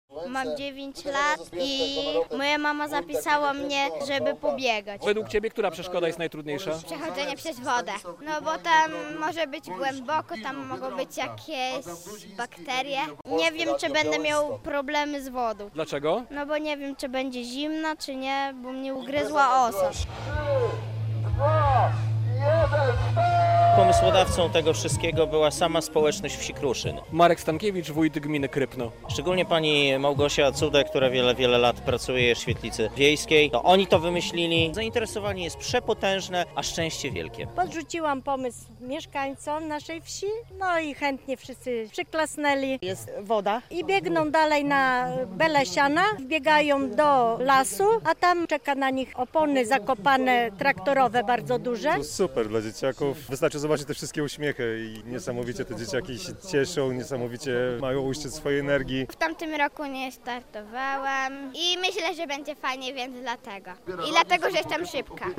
Brave Kid w Kruszynie- relacja